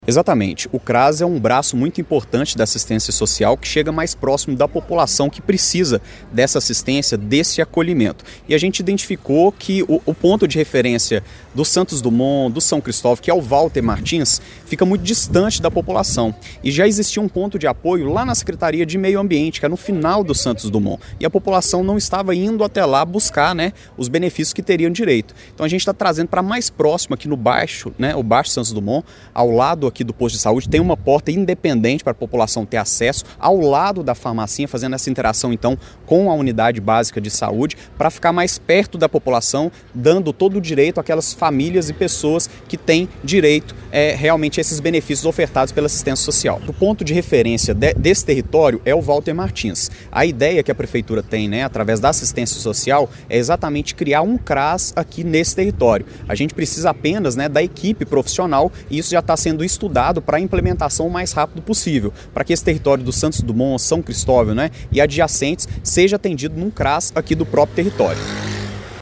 O vice-prefeito Luiz Fernando de Lima (Cidadania) reforçou que o novo espaço responde a uma necessidade antiga da região.